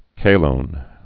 (kālōn, kălōn)